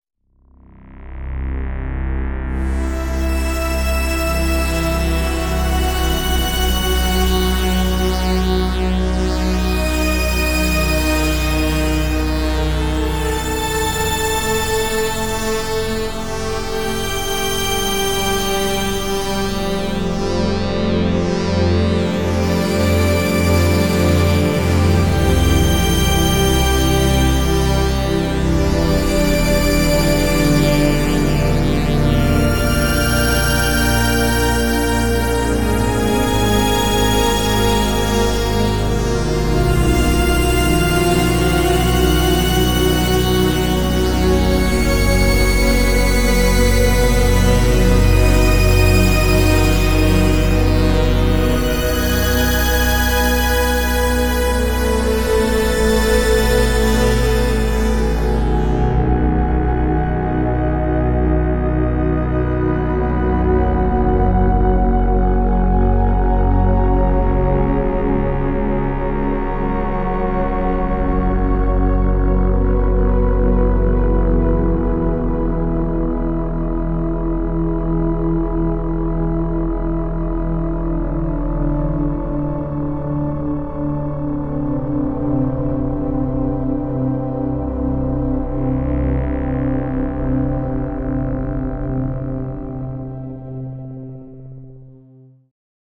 Module music I've composed over the years using OpenMPT.